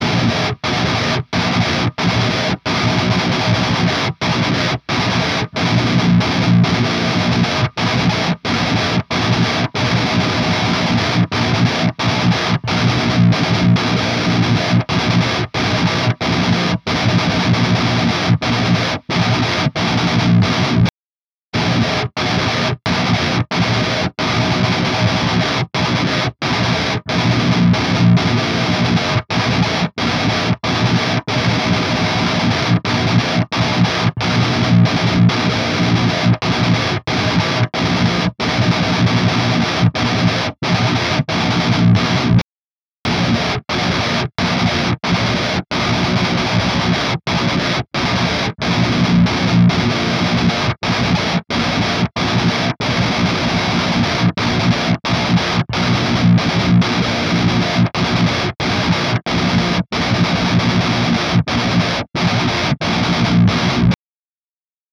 На картинках наглядная разница в АЧХ кемпера с реальным ампом и "скорректированного" импульсом сигнала с кемпера с реальным ампом. ну и пример звуковой с 00.00 - оригинальный амп, с 00.21 - "скорректированный" KPA, и с 00.43 - Кемпер как есть ICI test.mp3